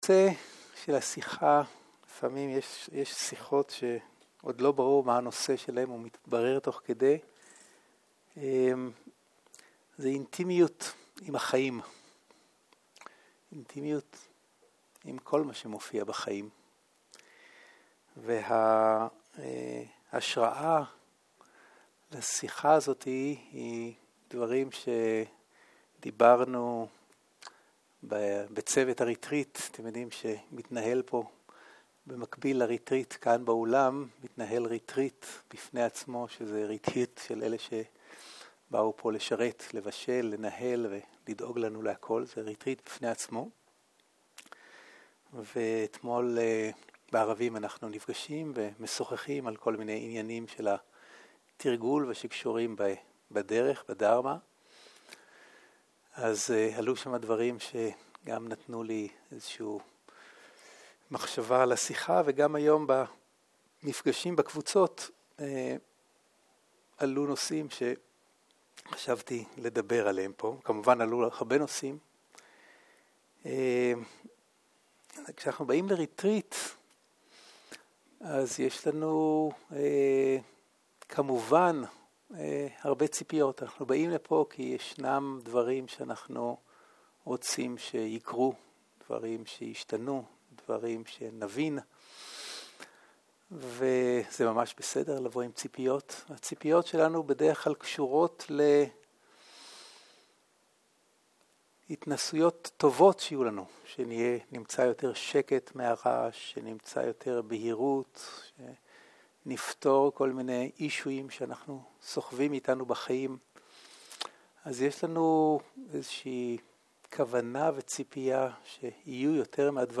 ערב - שיחת דהרמה - אינטימיות עם החיים
סוג ההקלטה: שיחות דהרמה